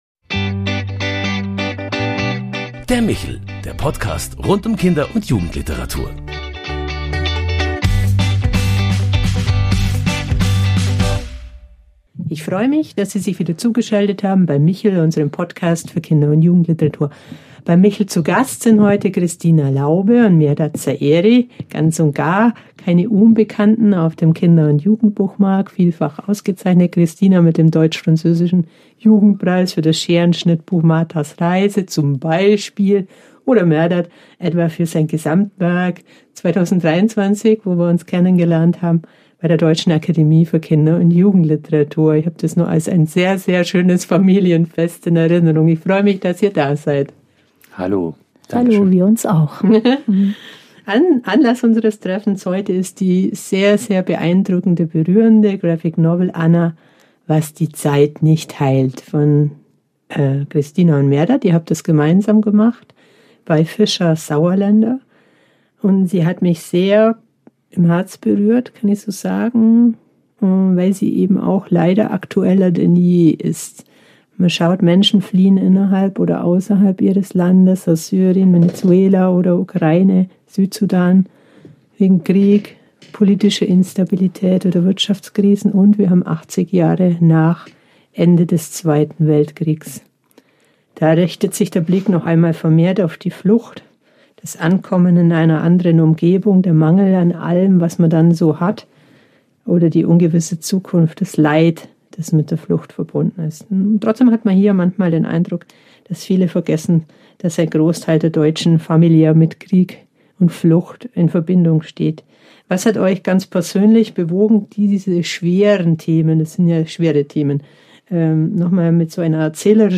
Die Folge macht deutlich, warum Geschichten über Krieg, Flucht und Erinnerung auch 80 Jahre nach dem Ende des Zweiten Weltkriegs nichts von ihrer Aktualität verloren haben – und wie Literatur Empathie schafft und Brücken zwischen Generationen baut. Hören Sie rein in ein Gespräch über Kunst, Geschichte und die Verantwortung des Erinnerns.